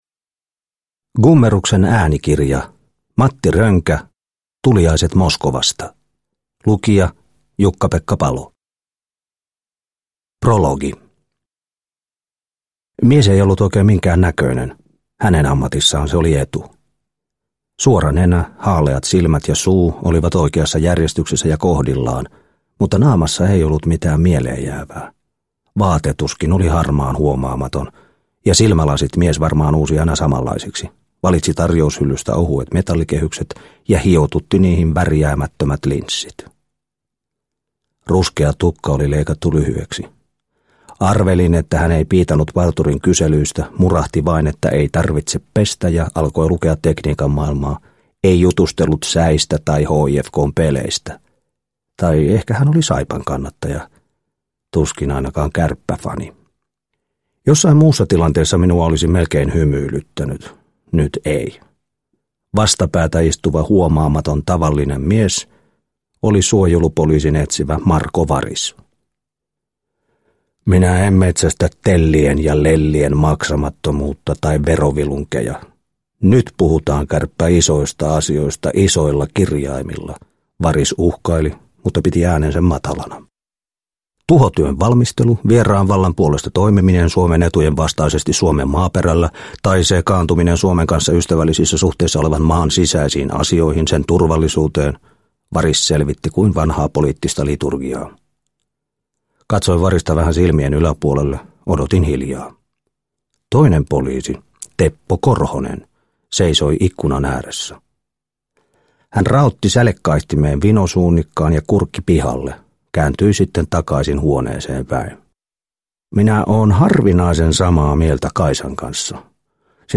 Tuliaiset Moskovasta – Ljudbok – Laddas ner